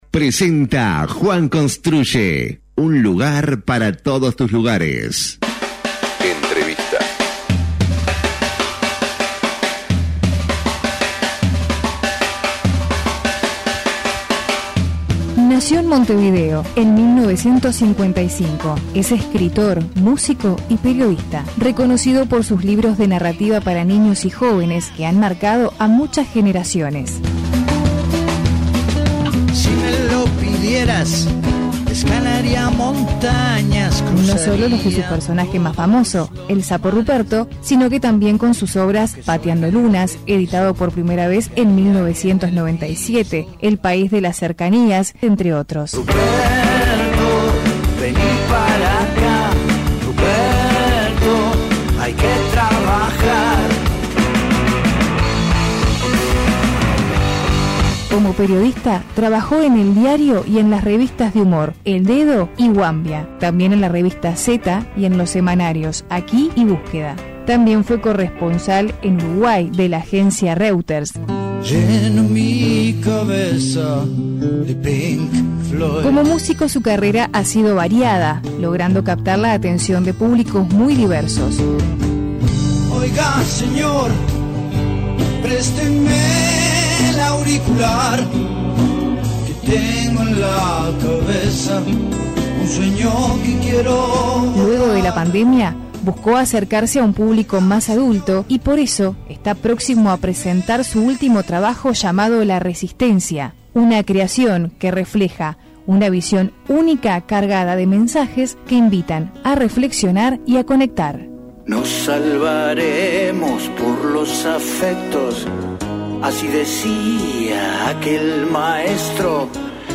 Entrevista completa aquí:
El músico, escritor y periodista Roy Berocay contó en entrevista con Punto de Encuentro como fueron sus primeros pasos en la literatura infantil y como surgió la publicación del libro Ruperto Rocanrol.